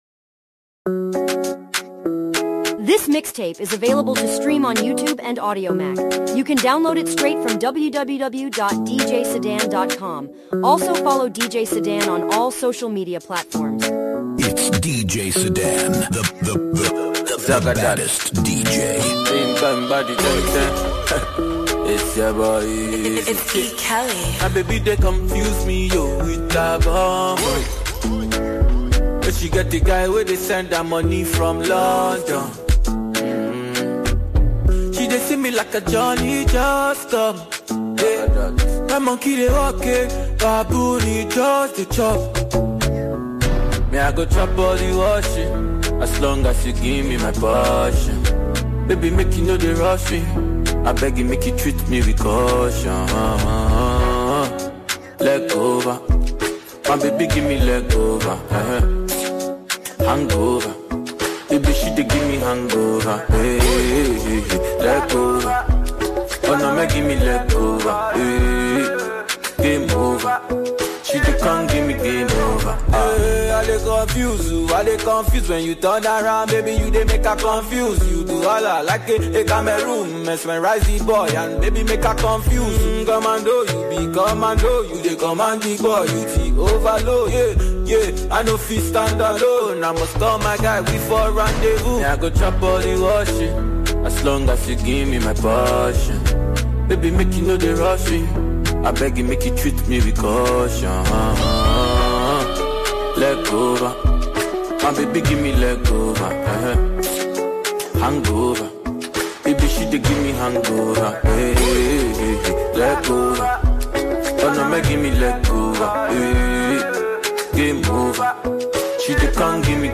Genre: Mixtape Release Format Type